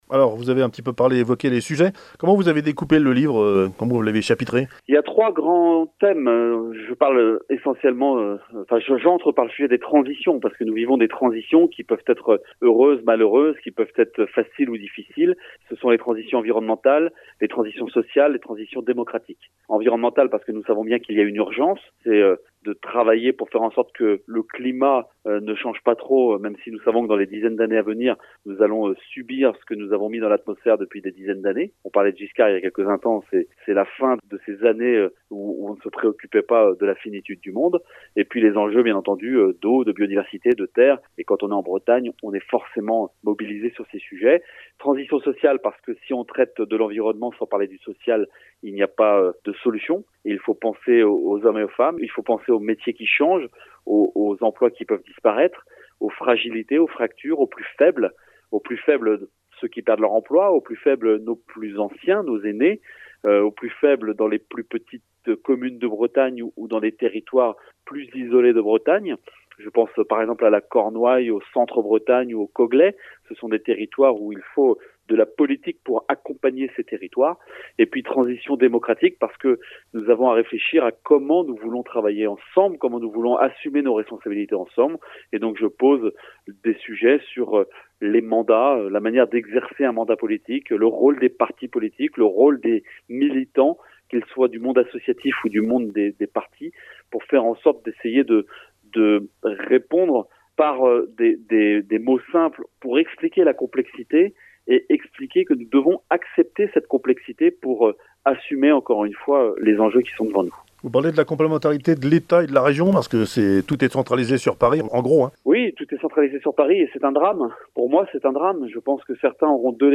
Interview de Loïc Chesnais-Girard – Auteur (mais aussi président du Conseil Régional de Bretagne)